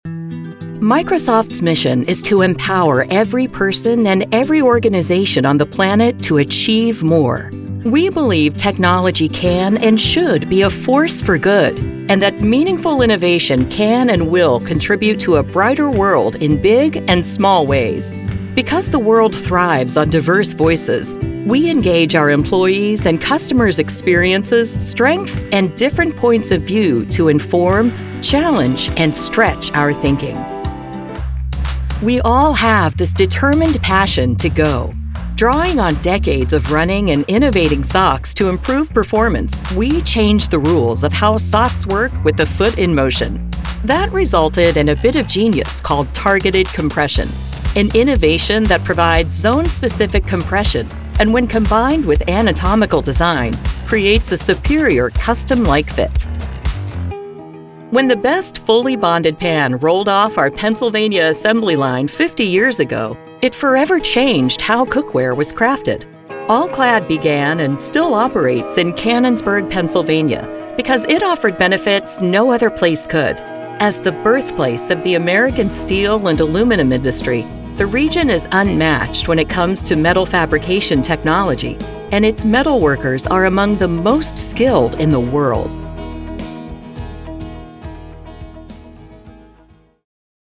Corporate
VO Demos